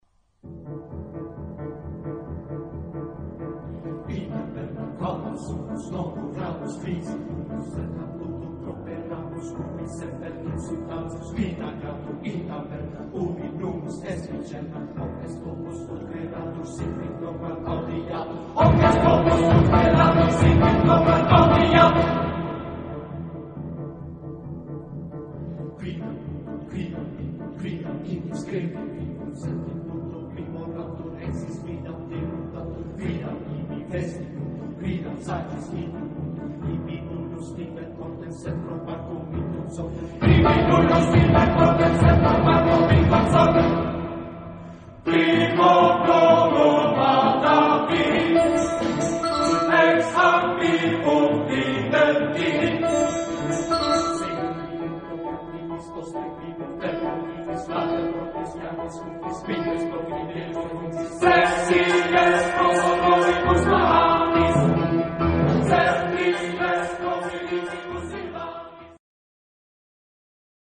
Genre-Style-Forme : Profane ; Chœur ; Chœur de cantate
Caractère de la pièce : joyeux
Type de choeur : TTBB  (4 voix égales d'hommes )
Instrumentation : Piano
Instruments : Piano à 4 mains (1)